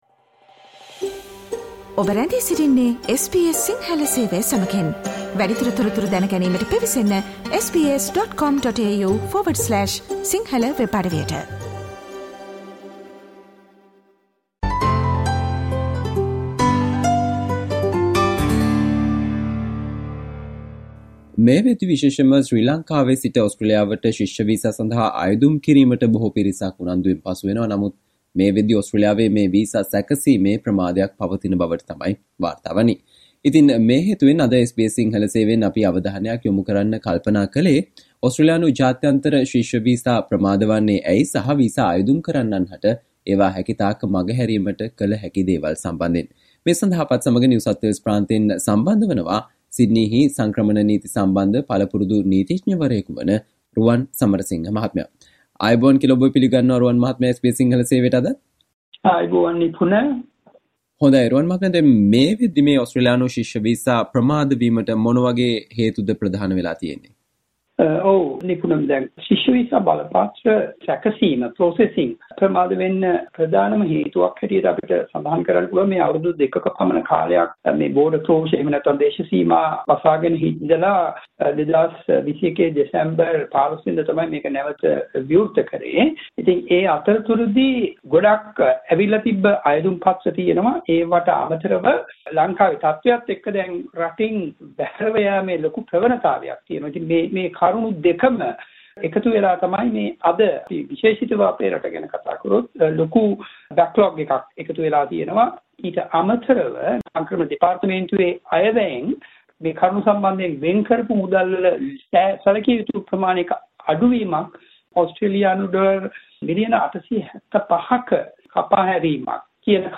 ඕස්ට්‍රේලියානු ජාත්‍යන්තර ශිෂ්‍ය වීසා ප්‍රමාද වන්නේ ඇයි සහ වීසා අයදුම් කරන්නන් හට ඒවා හැකිතාක් මගහැරීමට කළ හැකි දේවල් පිළිබඳව SBS සිංහල සේවය සිදු කල සාකච්චාවට සවන්දෙන්න